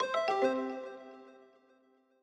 Longhorn Ten Beta - Notify System Generic.wav